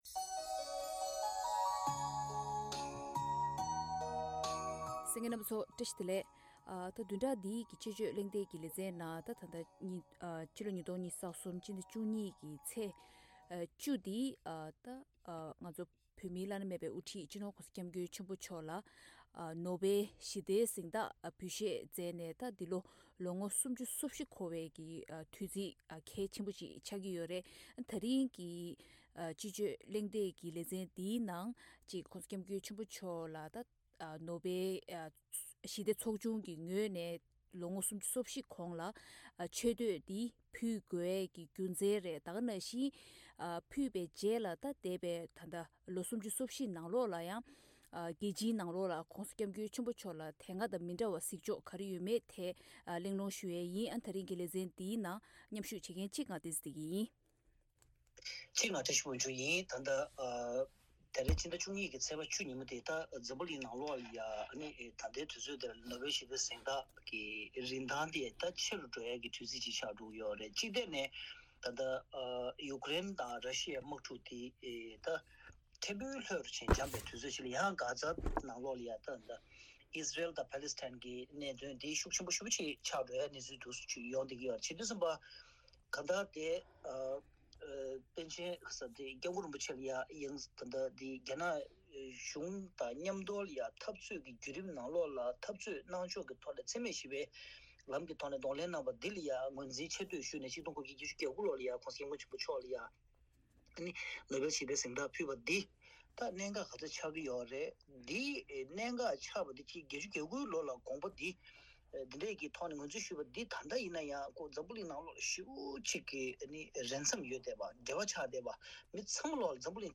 ཐེངས་འདིའི་དཔྱད་བརྗོད་གླེང་སྟེགས་ཀྱི་ལས་རིམ་ནང་།